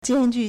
艰巨 (艱巨) jiānjù
jian1ju4.mp3